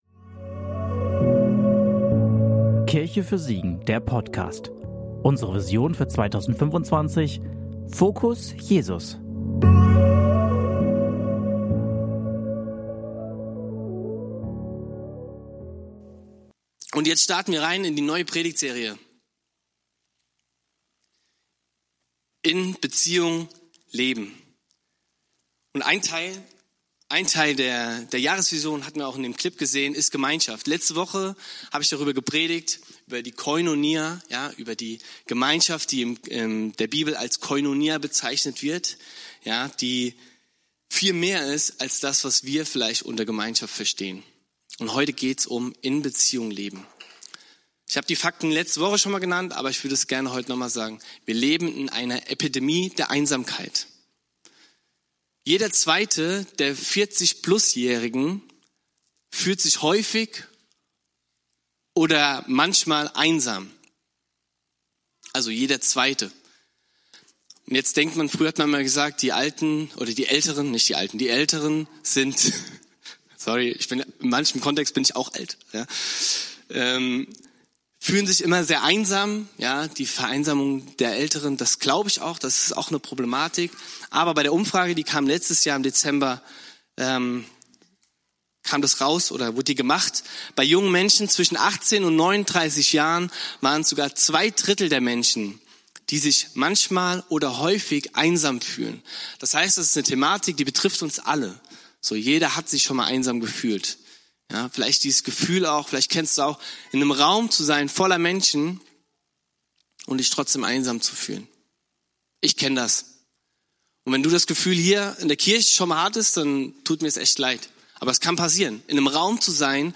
Freundschaft - Predigtpodcast